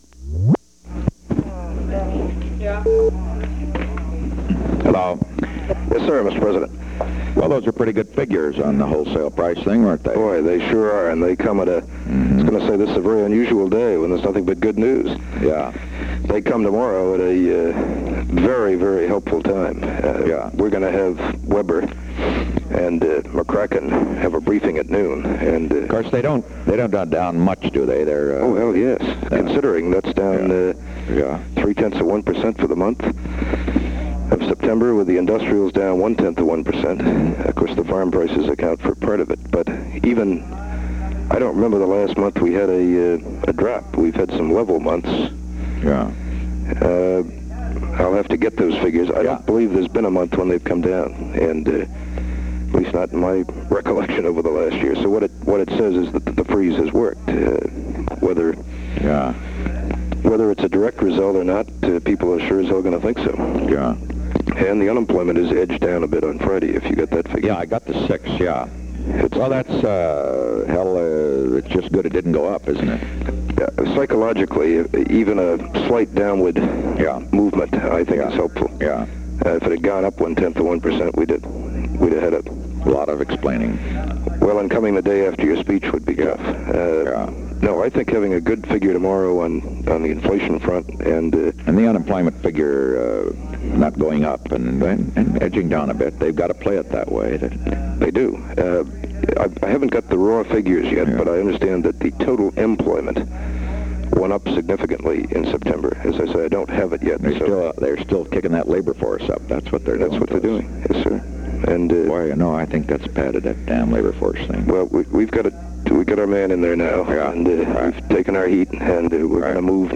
Conversation No. 10-94 Date: October 6, 1971 Time: 3:41 pm - 4:00 pm Location: White House Telephone The President talked with Charles W. Colson.